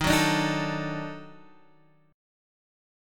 D#mM7b5 Chord